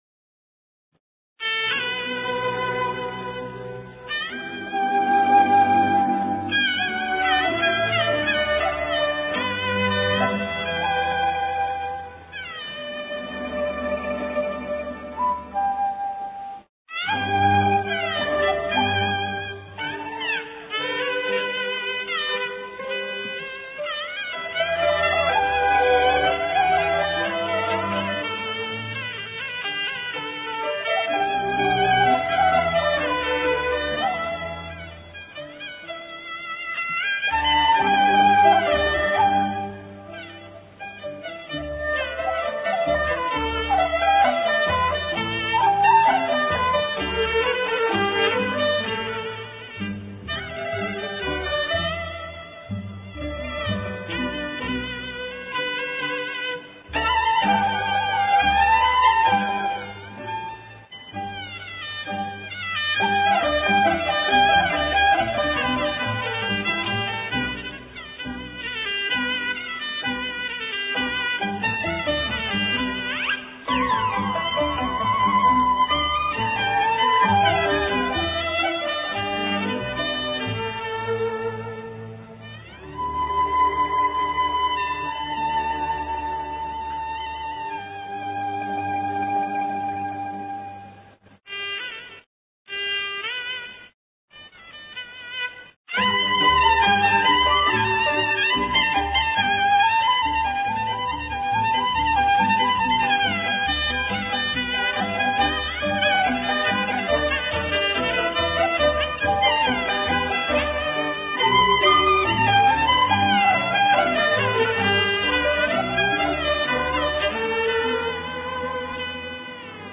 領奏：高胡
樂曲一開始是一段恬靜的旋律，表現了幽靜的森林暮色。然後突然出現一陣鳥鳴聲，這是一段華彩，猶如群鳥滑翔，投入林中。
曲終之前，突然一聲鳥鳴，表現還有一只小鳥沒有入睡。